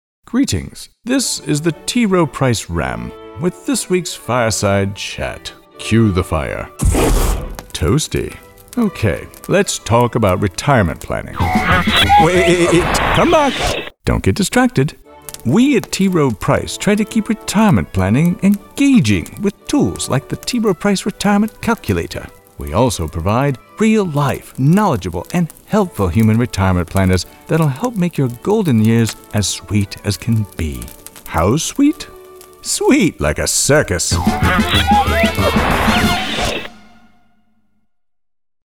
Commercial Demo
A voice actor with a smooth, trustworthy, and personable tone.
Character, info | Investing
• Microphone: Shure SM7B